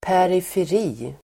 Uttal: [pärifer'i:]